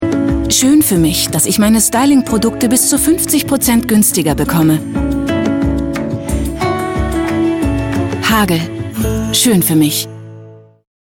deutsche Sprecherin,Hörspiel,Computerspiele,Werbung,Voice over,Imagefilm,Hörbuch variabel von rauchig dunkel bis spritzig frisch, sexy.
Sprechprobe: Werbung (Muttersprache):